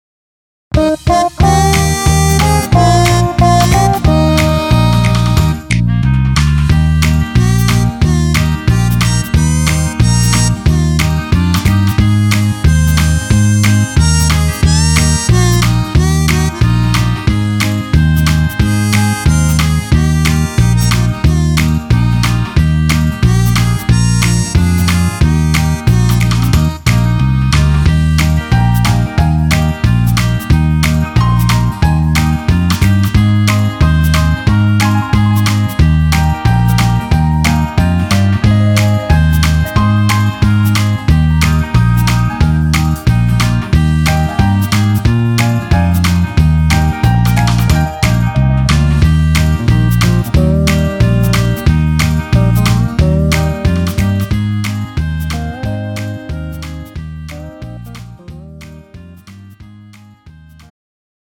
음정 -1키 3:06
장르 pop 구분 Pro MR